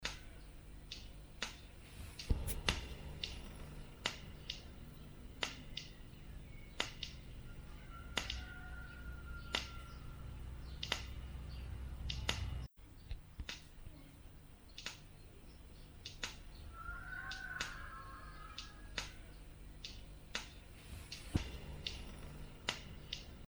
this clicking problem is plaguing my recordings and i'm struggling to figure it out. here's a recording of it. does anybody recognise it? i've tried two...
Attached is the noise cleaned up and boosted. That is surely some sort of clock? I reckon they are 1sec intervals?
Attachments ticking01.mp3 ticking01.mp3 916.6 KB · Views: 90